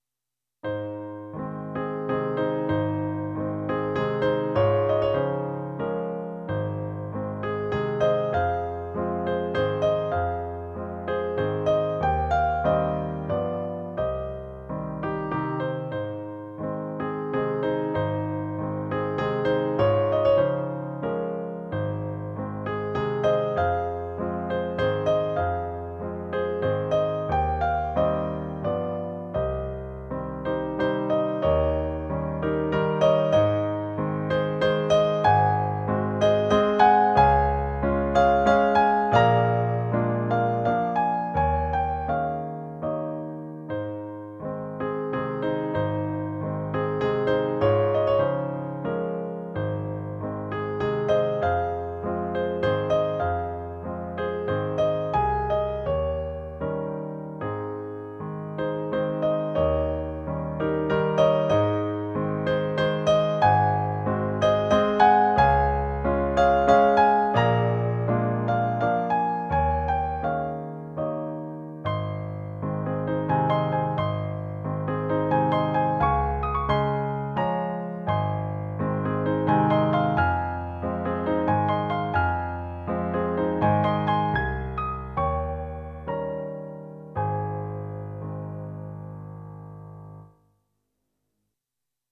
シンプルな構成と覚えやすいメロディーが多くの人に好まれているようです。
水色の丸で囲った4度音程をクッションとして引き継いでいくことで、穏やかな着地が図られています。
明るく可愛らしい曲調にブラームス独特の技巧が加わって、より優美さが高められている楽曲です。